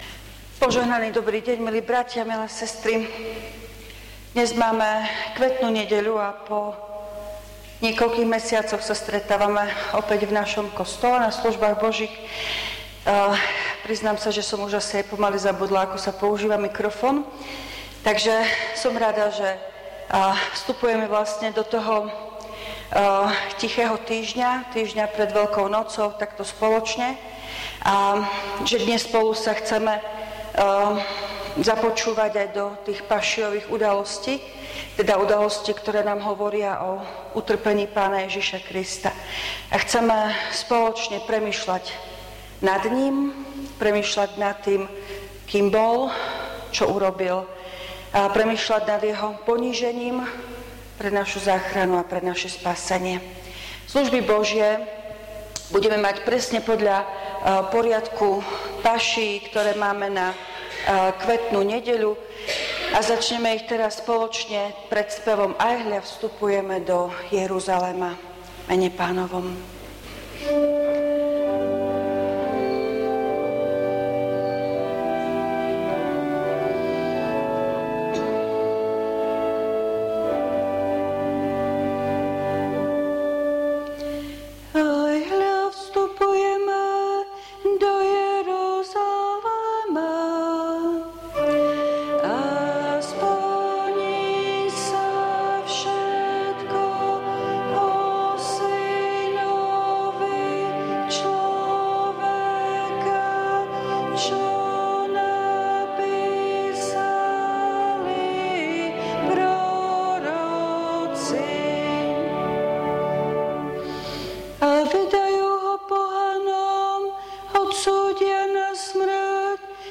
V nasledovnom článku si môžete vypočuť zvukový záznam zo služieb Božích – 6. nedeľa pôstna – Kvetná nedeľa.